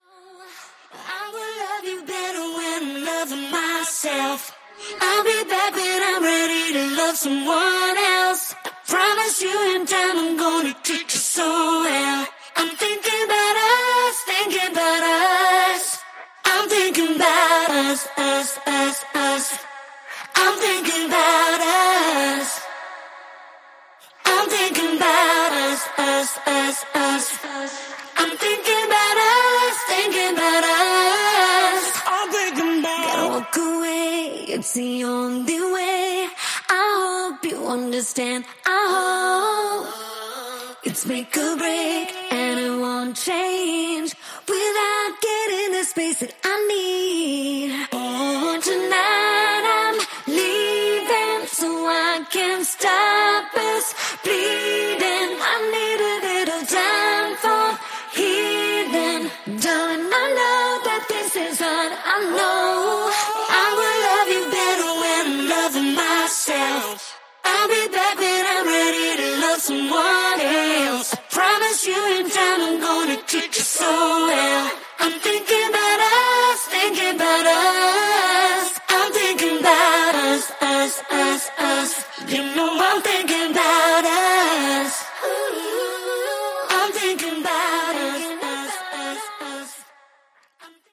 ジャンル(スタイル) POP / HOUSE